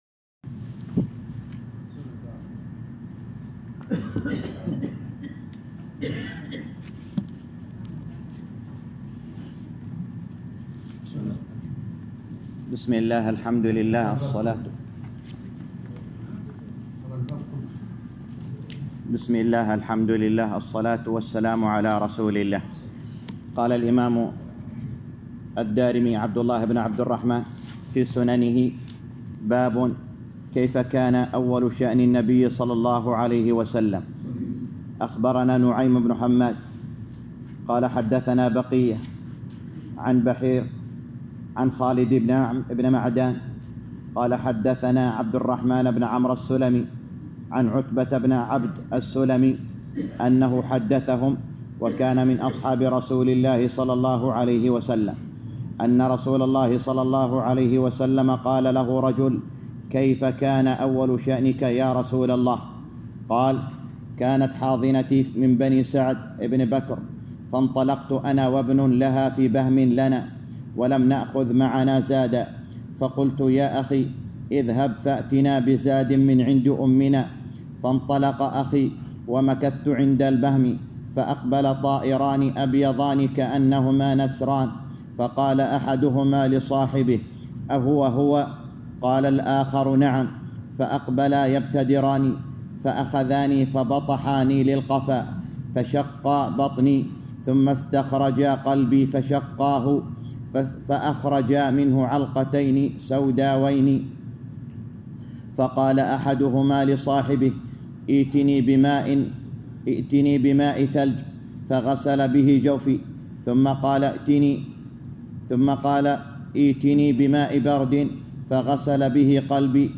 شرح سنن الدارمي (الشرح الجديد) الدرس الخامس